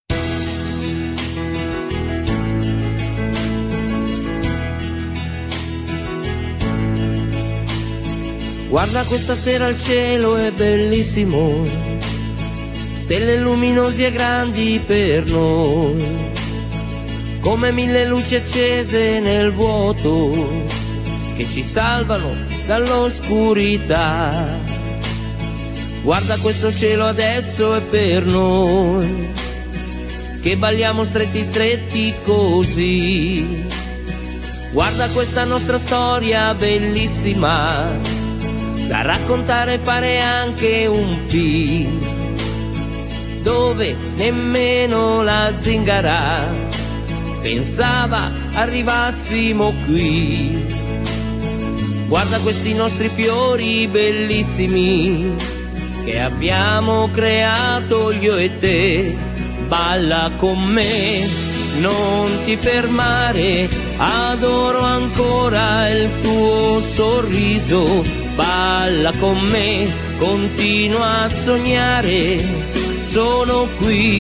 Liscio folk